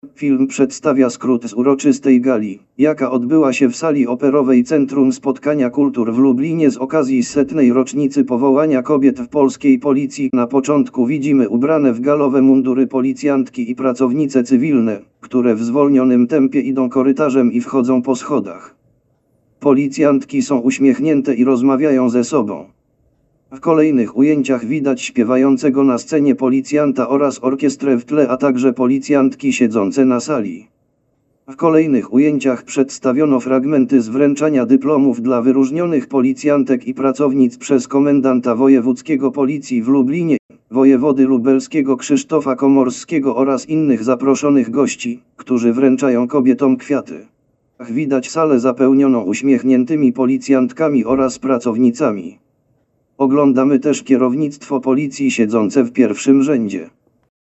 Nagranie audio Audiodeskrypcja filmu setna rocznica powołania Policji Kobiecej